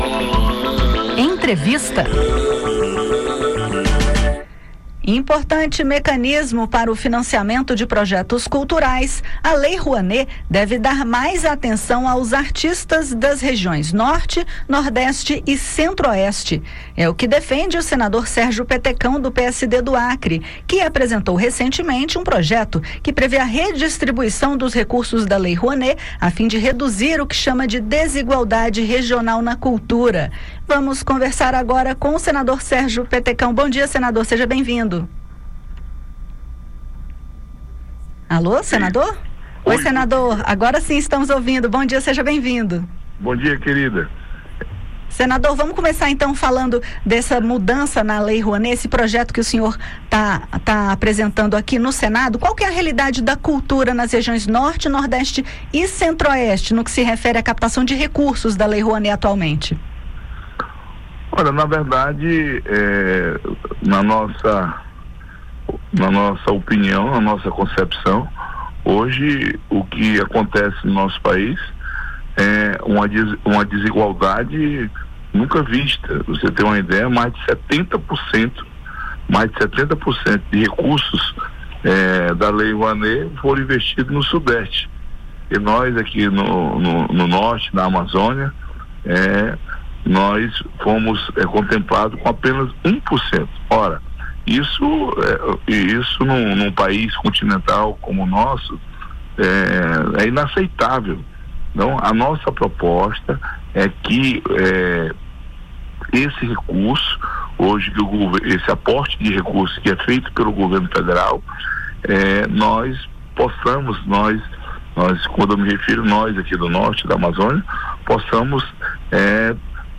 É o que defende o senador Sérgio Petecão (PSD-AC), que apresentou recentemente um projeto (PL 1718/2025) que prevê a redistribuição dos recursos da Lei Rouanet a fim de reduzir o que chama de "desigualdade regional na cultura“. Acompanhe a entrevista com o senador e entenda as mudanças propostas.